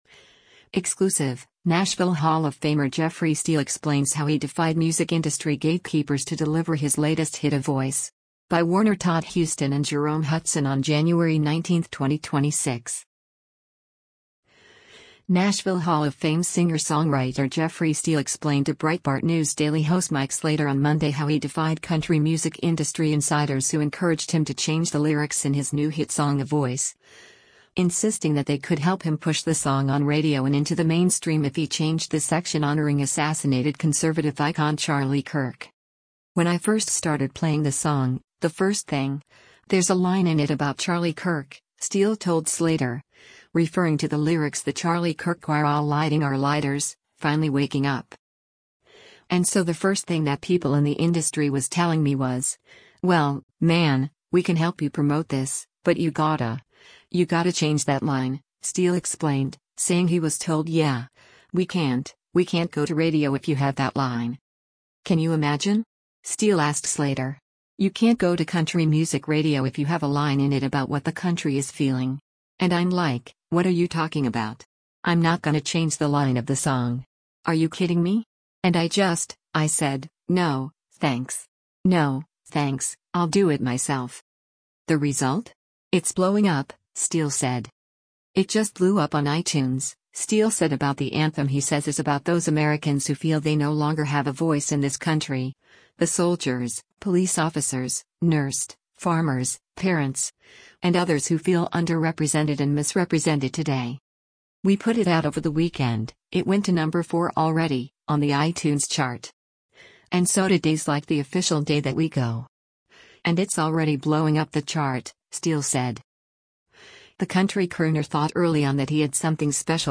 Nashville Hall of Fame singer-songwriter Jeffrey Steele explained to Breitbart News Daily host Mike Slater on Monday how he defied country music industry insiders who encouraged him to change the lyrics in his new hit song “A Voice,” insisting that they could help him push the song on radio and into the mainstream if he changed the section honoring assassinated conservative icon Charlie Kirk.